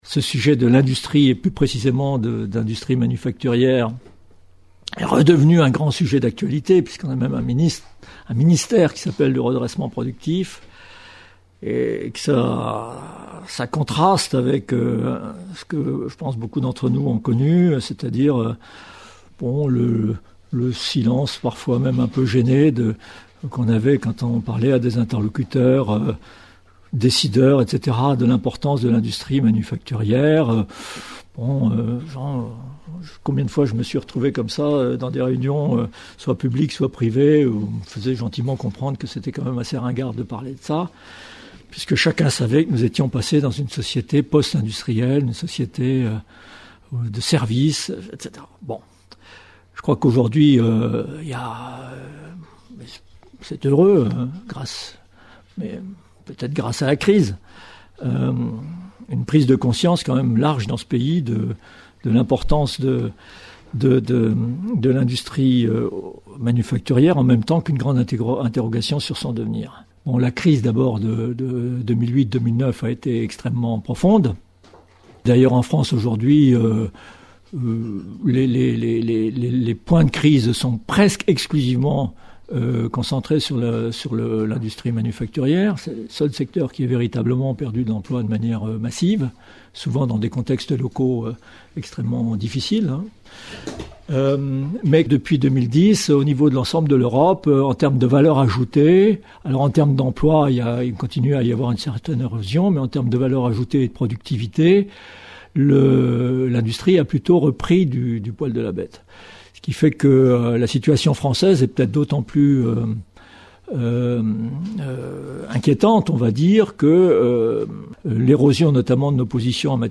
En réalité, nous sommes engagés dans une vaste mutation qui nous mène vers une société "hyper industrielle", où les produits sont des assemblages de biens et de services, et où la numérisation remodèle en profondeur les systèmes de production mondialisés, mais toujours appuyés sur les ressources des territoires. La conférence explore ces processus, en accordant une place de choix aux imaginaires.